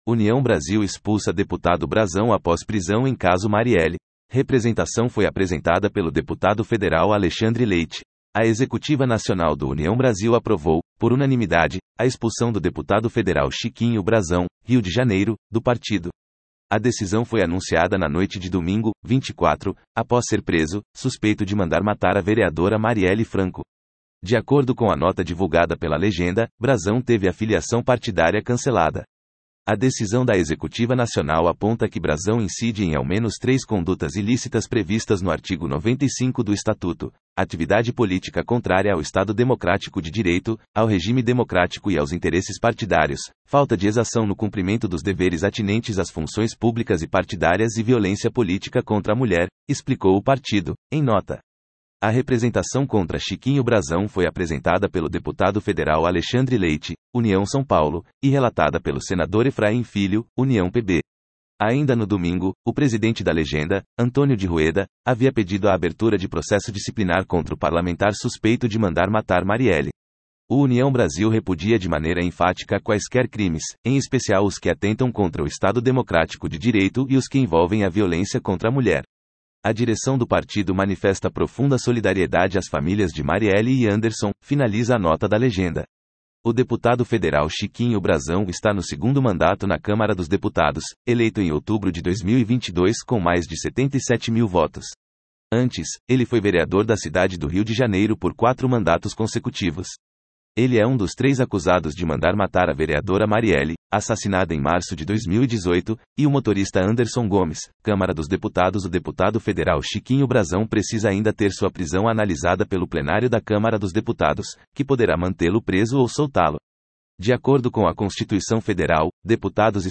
Audio version of União Brasil expulsa deputado Brazão após prisão em caso Marielle